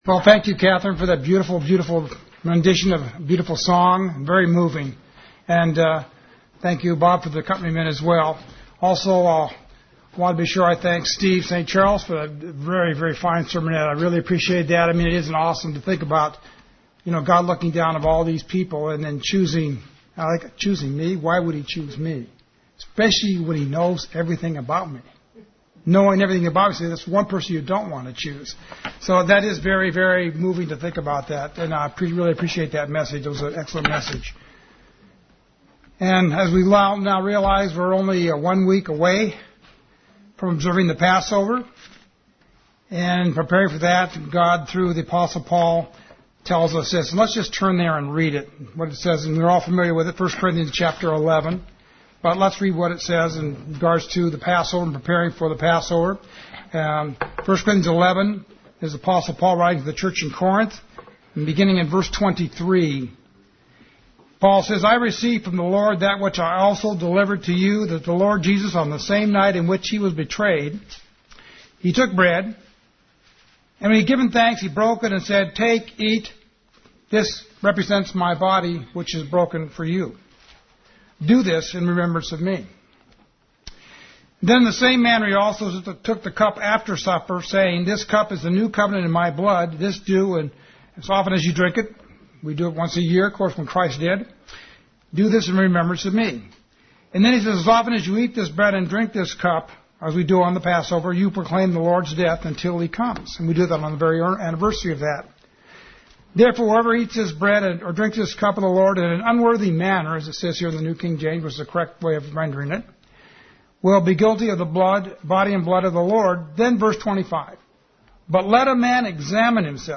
The most important area being: do we love our brother? sermon Transcript This transcript was generated by AI and may contain errors.